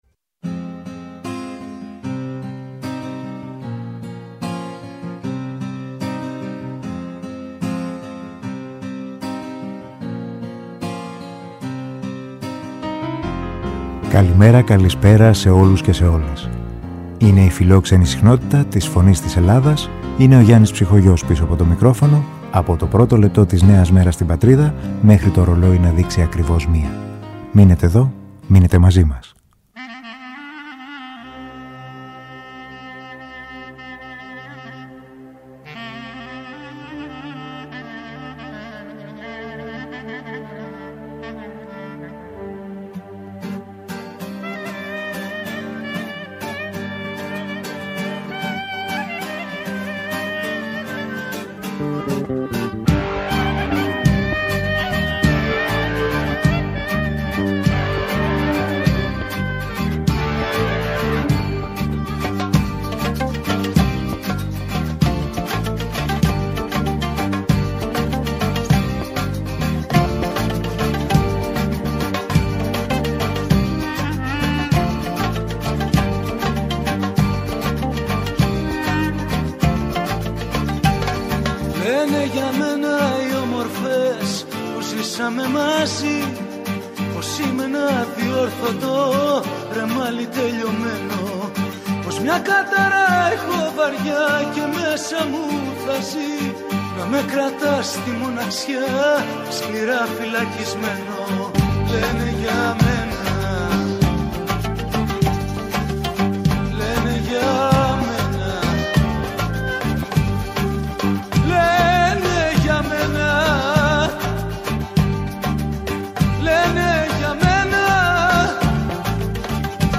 μαζί με τις ακροάτριες και τους ακροατές της Φωνής της Ελλάδας.